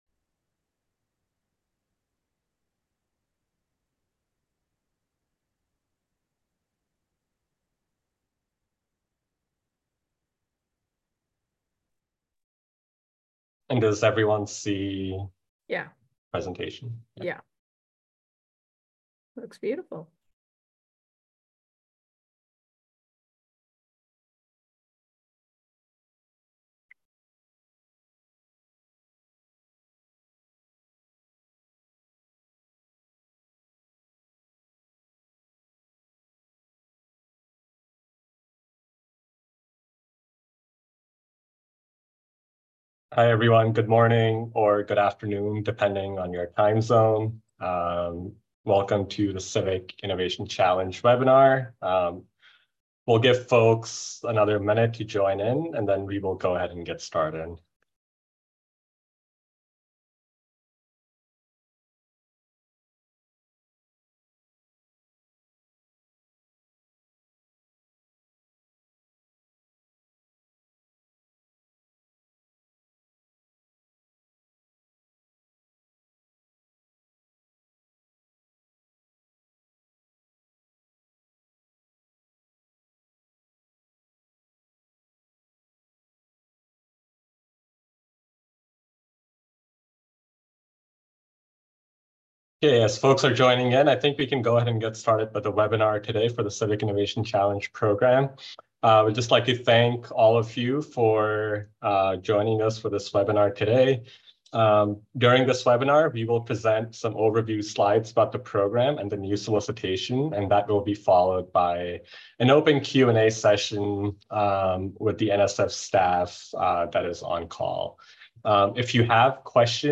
Civic Innovation Challenge Webinar for 2024 Solicitation